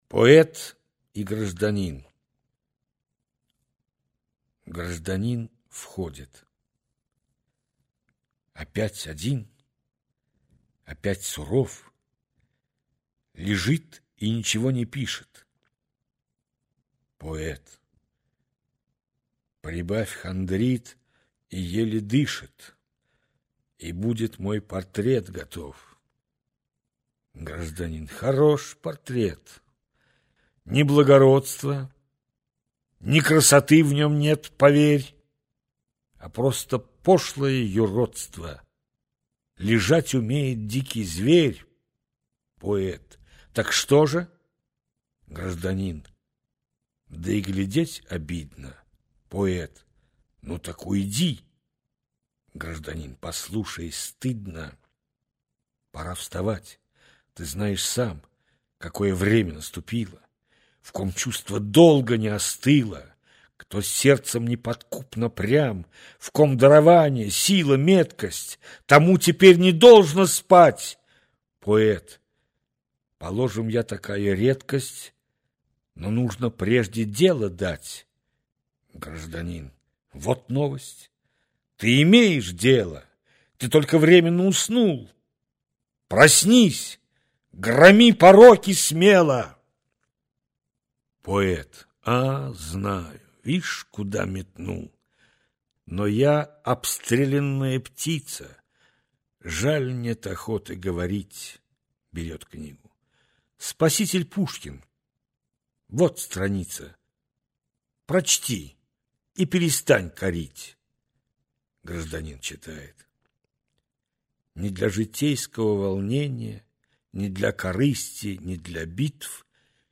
Аудиокнига Русская поэзия XIX в. | Библиотека аудиокниг
Прослушать и бесплатно скачать фрагмент аудиокниги